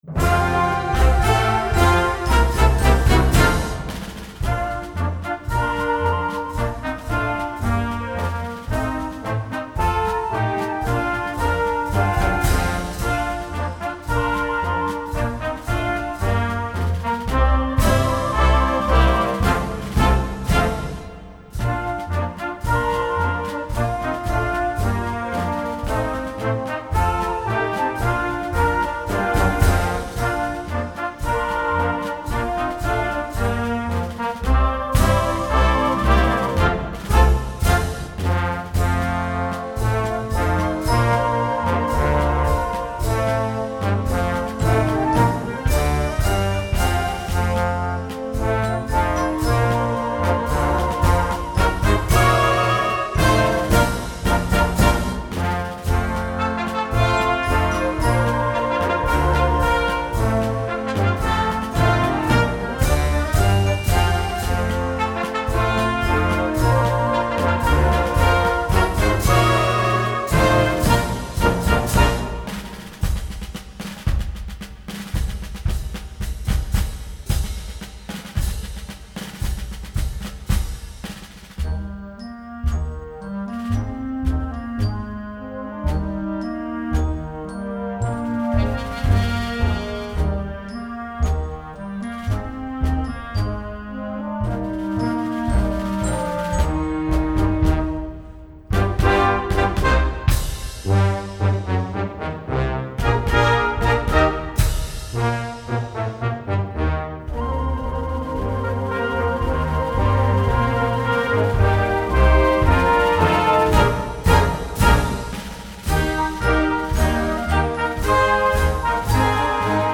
Marsch für Jugendkapelle
Blasorchester